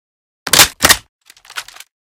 unjam_empty.ogg